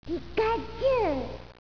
PIKACHU4.WAV Pikachu saying her name slowly 18,3 Kb